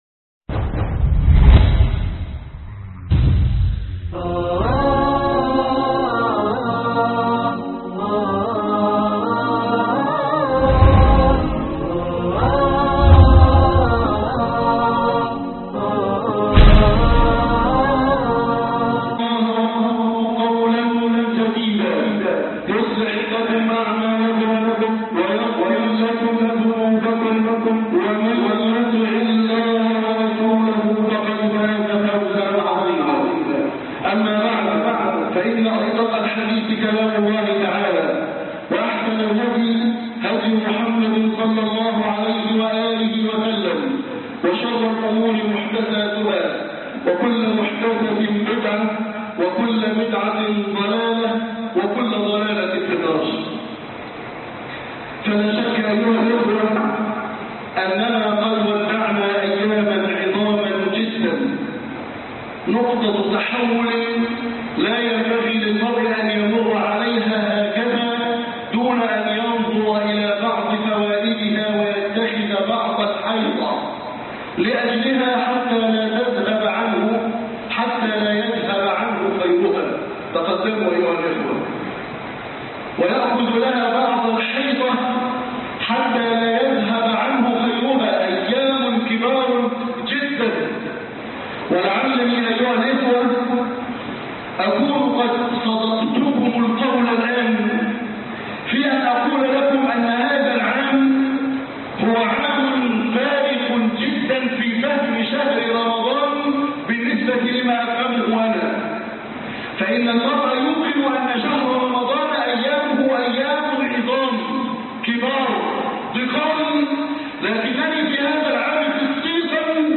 اياك ونقض العهد مع الله بعد رمضان - خطب الجمعة